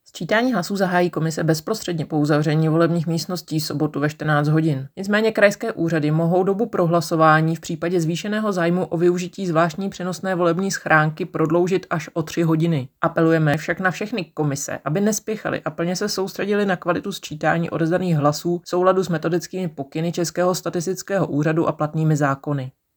Vyjádření Marka Rojíčka, předsedy ČSÚ, soubor ve formátu MP3, 906.17 kB